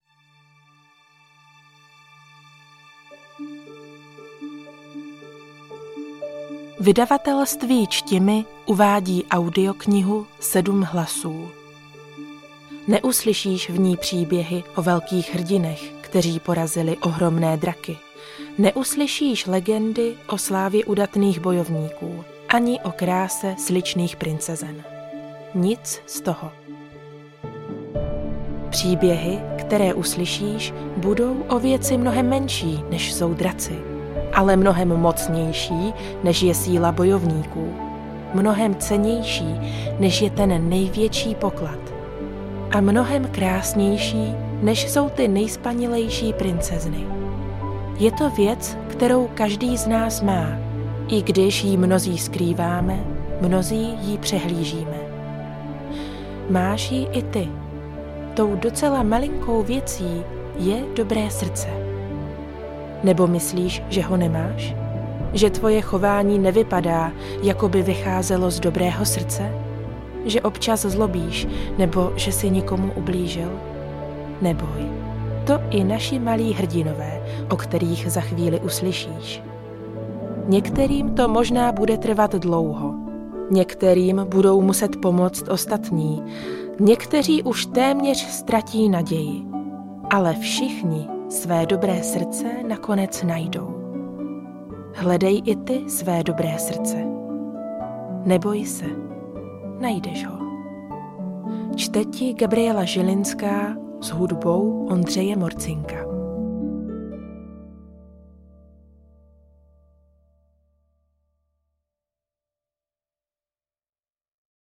7 hlasů audiokniha
Ukázka z knihy